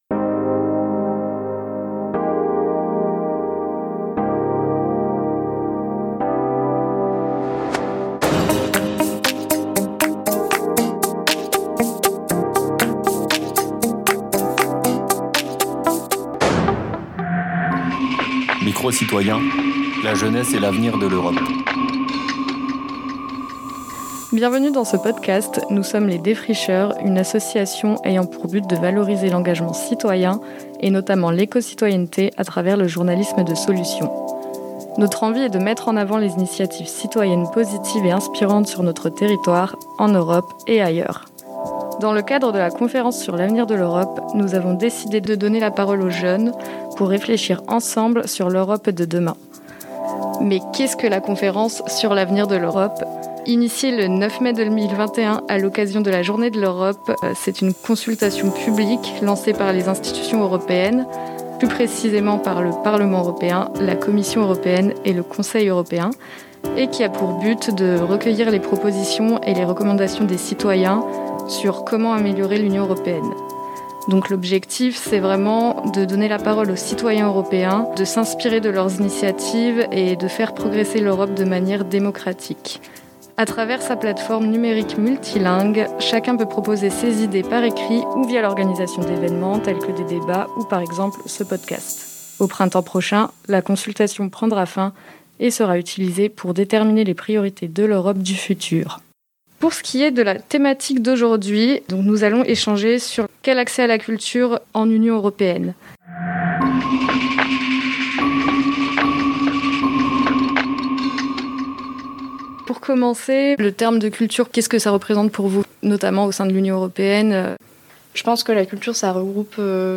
Un micro-trottoir avec quatre débats sur la culture, la démocratie, l’environnement, l’emploi des jeunes, etc.